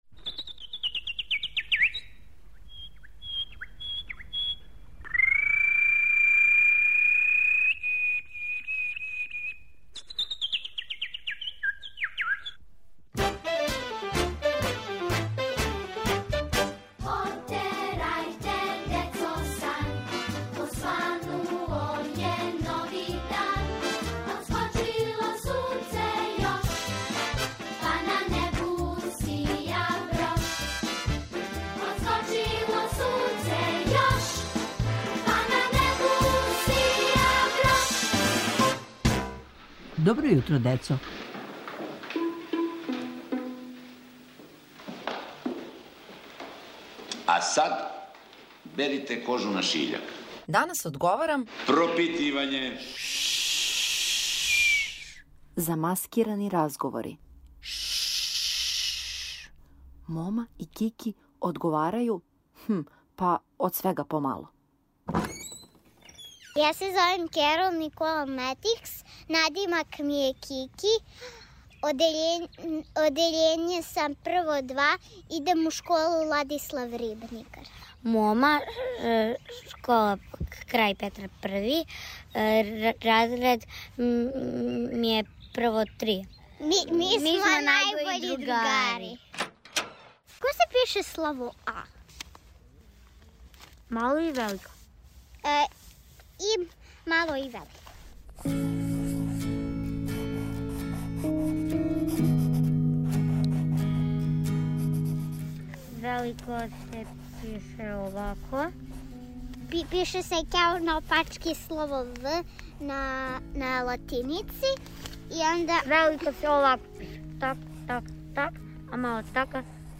У серијалу "Данас одговарам", ђаци се преслишавају, одговарају лекције, уче наглас.